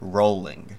ROH-ling;[1] CH OBE born 31 July 1965), known by her pen name J. K. Rowling, is a British author and philanthropist.